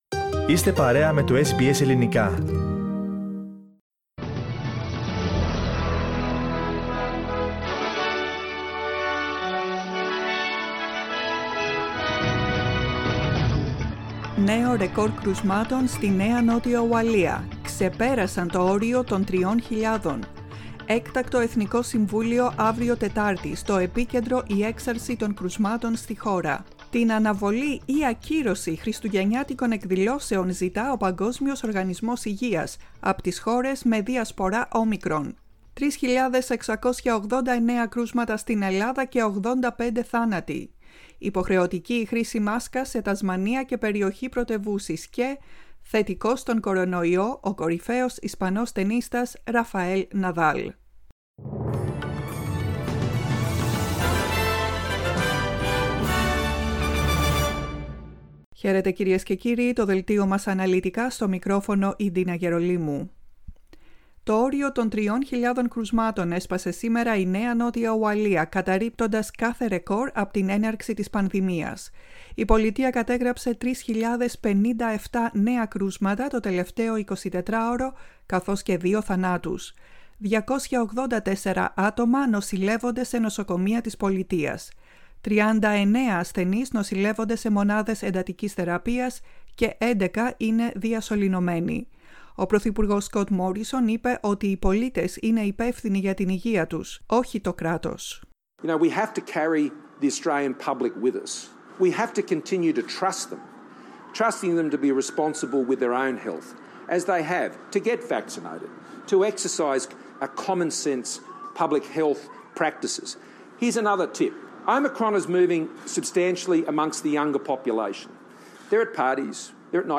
News bulletin in Greek.